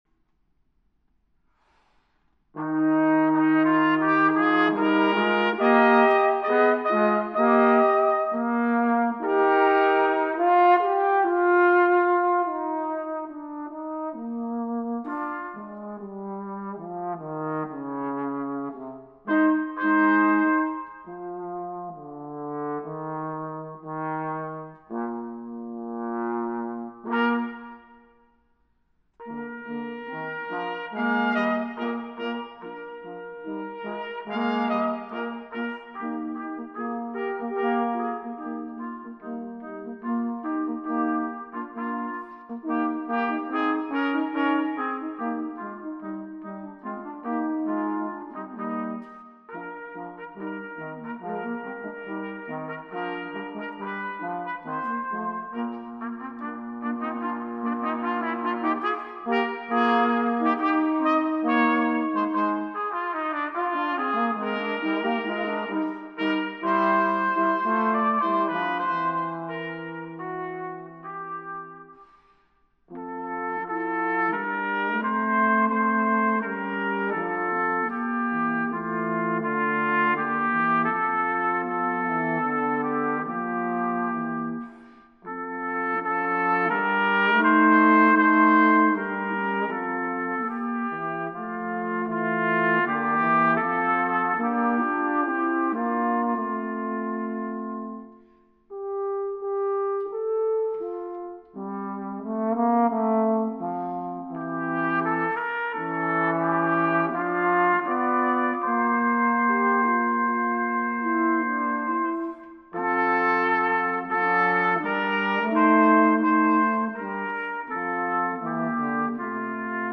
Voicing: Brass Trio